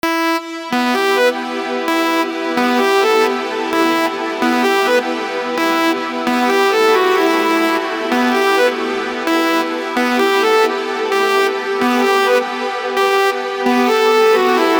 legato_ex_1.mp3